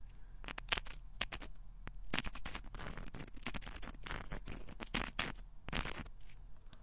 家居质感 " 在岩石上研磨一分钱
描述：在岩石上磨一分钱
Tag: 岩石 质地 家庭